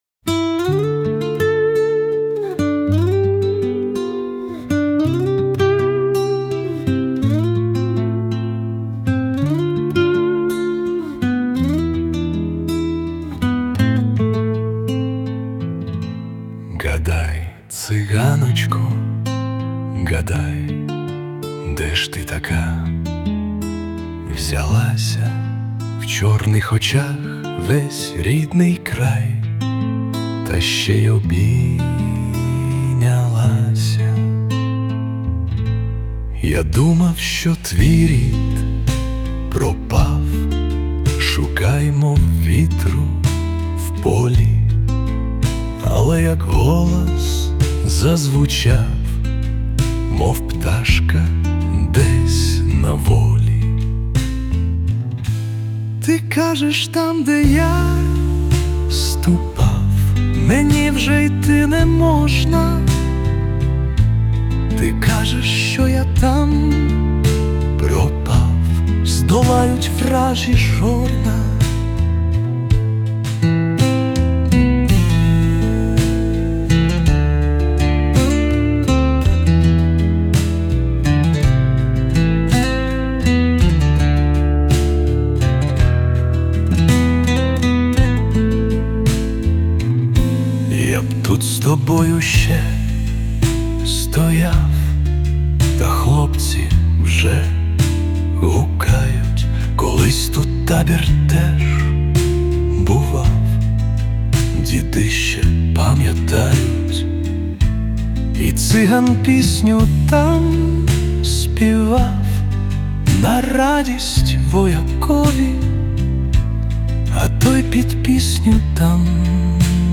Використано допомогу SUNO. Кавер.